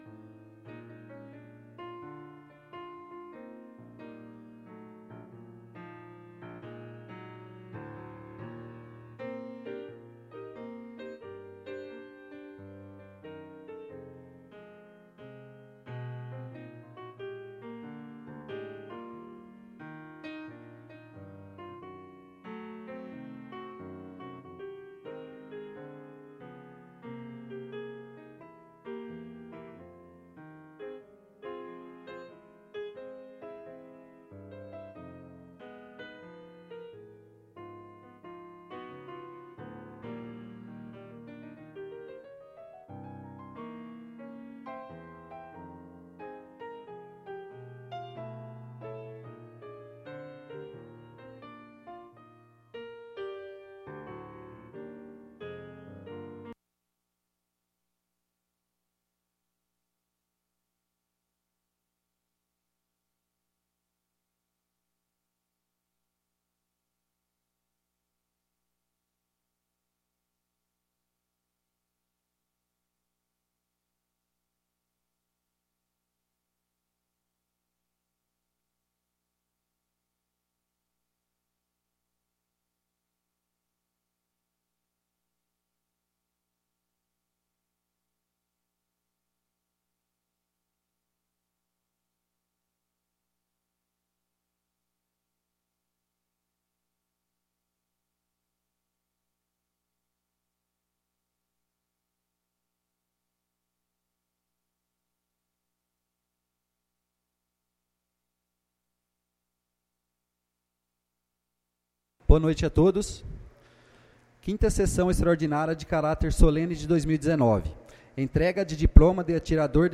Sessões Solenes